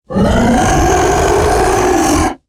Хотя его существование не доказано, эти аудиозаписи помогут вам представить его голос: от глухого рычания до странных шорохов.
Громкий крик Бигфута в заснеженной глуши